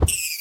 rabbit_bunnymurder.ogg